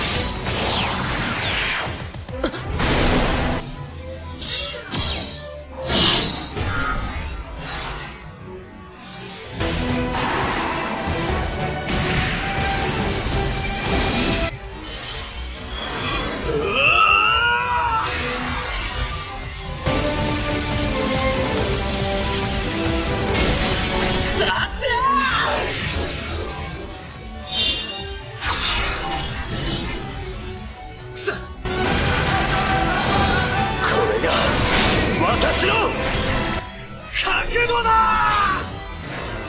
The music is relatively loud.
A great deal can be removed by isolating the center but not all and what’s left has some artefacts.
I’ve applied “Center isolation” with the most extreme “Strength” setting.
Here’s a sample file with alternating original/isolated center: